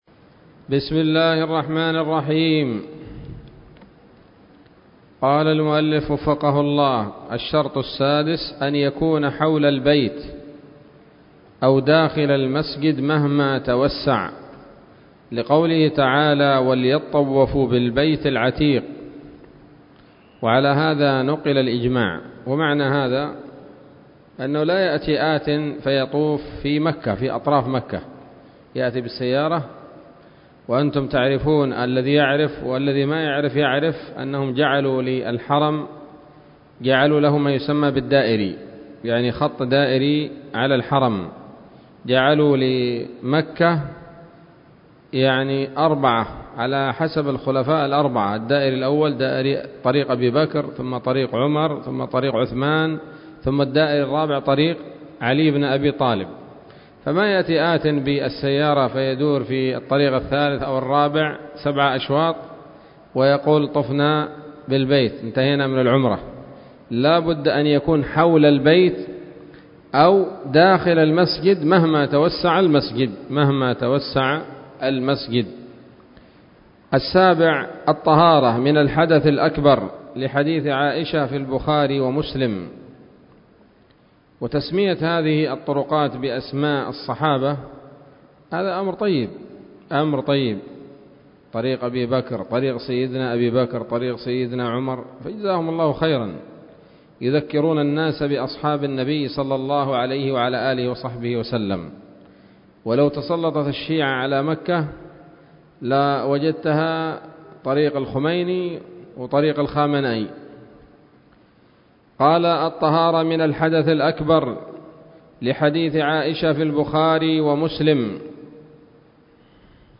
الدرس الثالث عشر من شرح القول الأنيق في حج بيت الله العتيق